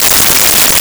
Arcade Movement 02.wav